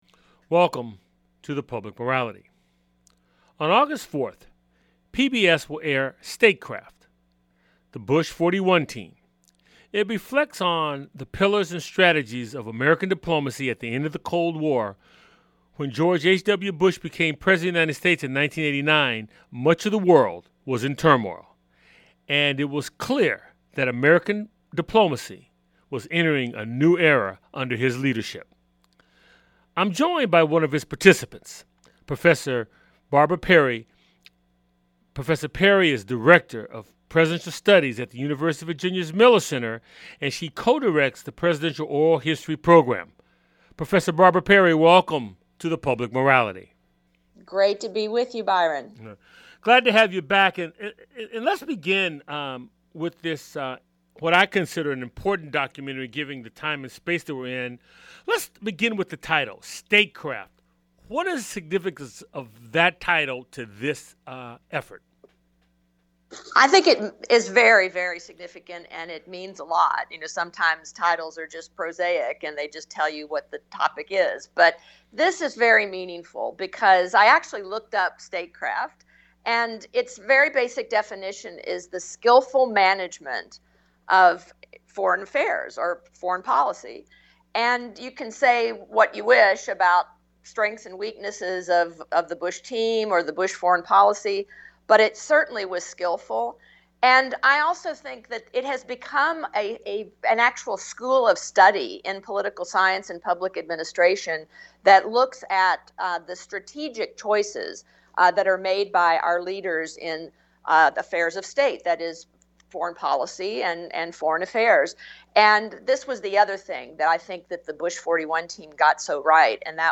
It's a weekly conversation with guest scholars, artists, activists, scientists, philosophers and newsmakers who focus on the Declaration of Independence, the Constitution and the Emancipation Proclamation as its backdrop for dialogue on issues important to our lives.